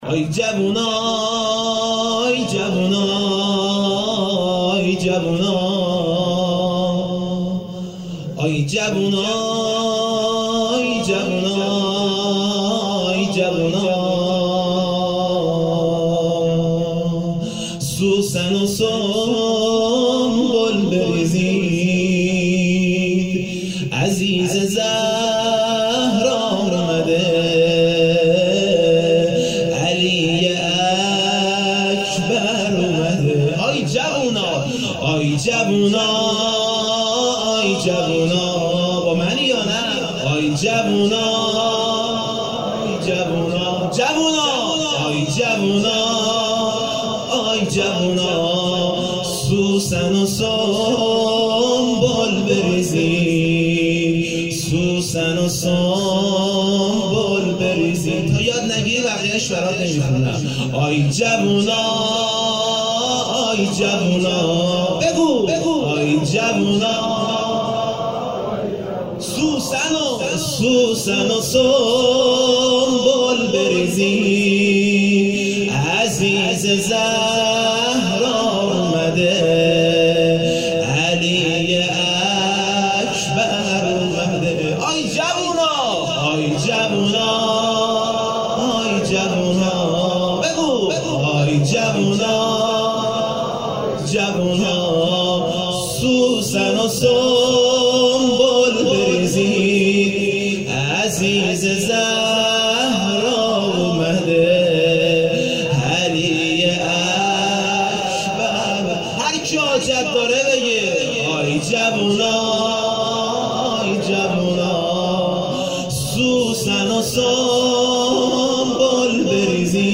2 0 مولودی خوانی